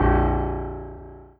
piano-ff-02.wav